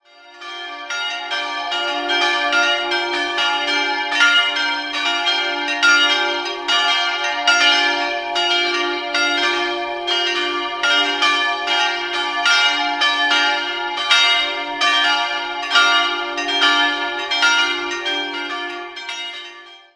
Idealquartett: d''-f''-g''-b'' Die kleine Glocke wurde 1733 von Matthias Perner in Eichstätt gegossen, die drei anderen stammen aus der Gießerei Bachert in Bad Friedrichshall aus dem Jahr 2000.